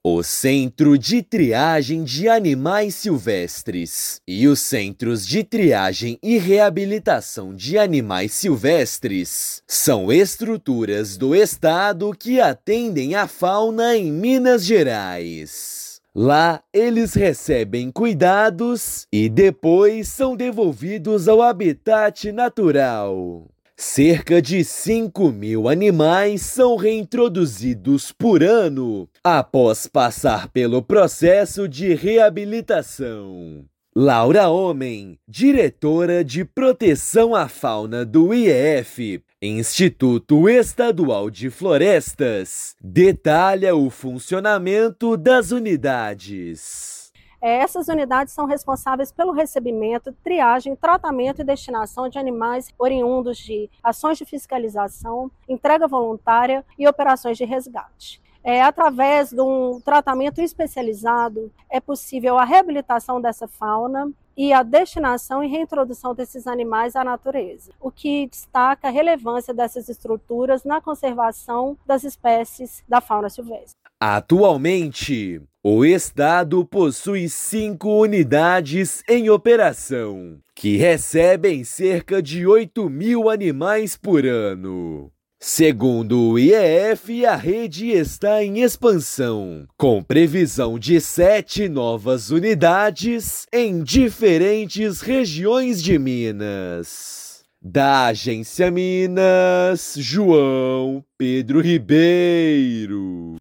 Centros de Triagem sustentam uma política contínua de conservação da biodiversidade no estado. Ouça matéria de rádio.